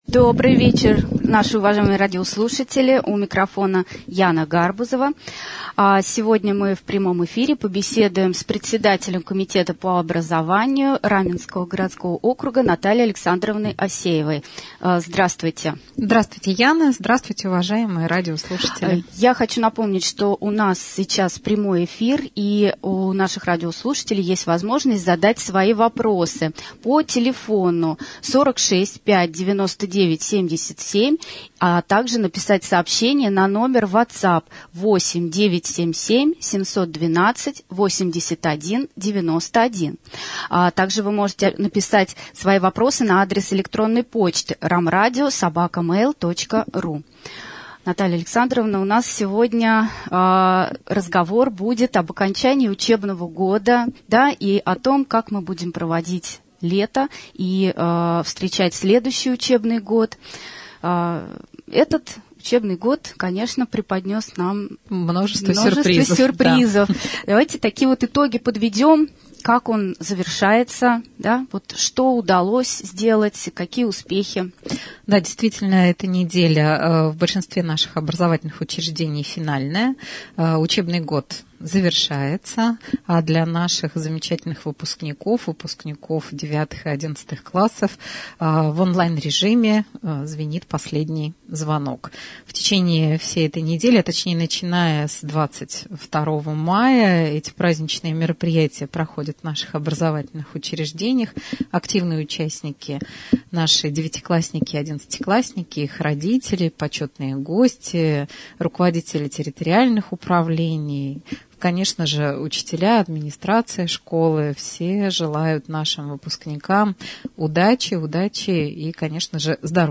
Председатель Комитета по образованию администрации Раменского г.о. стала гостем прямого эфира на Раменском радио 27 мая 2020 г.
prjamoj-jefir.mp3